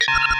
UIBeep_Computer Navigate.wav